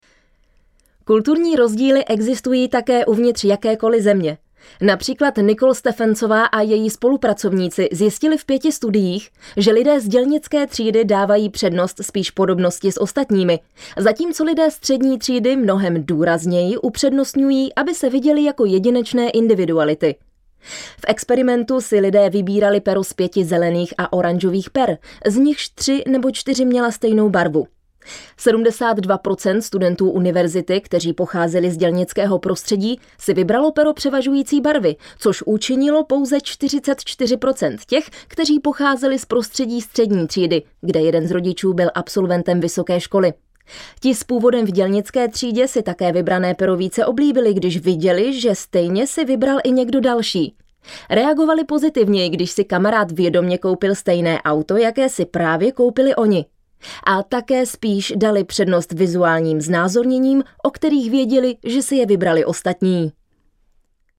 Profesionální ženský voiceover do vašeho videa (1minuta)
Ve svém studiu natočím voiceover pro jakýkoliv váš projekt!
Hledáte mladý ženský hlas?